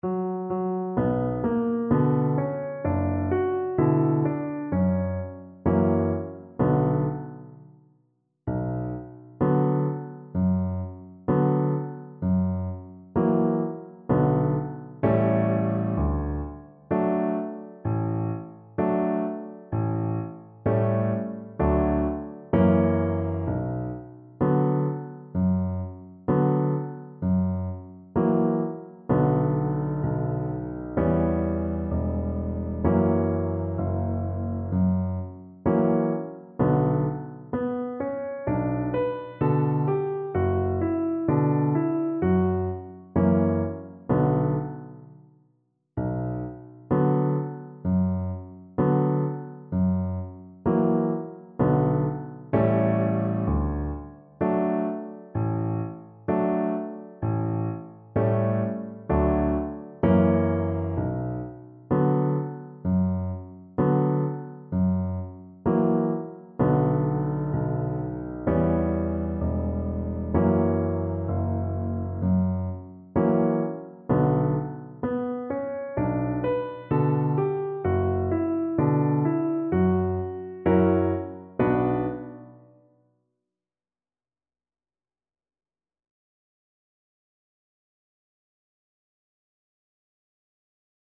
Cello
B minor (Sounding Pitch) (View more B minor Music for Cello )
Moderato
4/4 (View more 4/4 Music)
Traditional (View more Traditional Cello Music)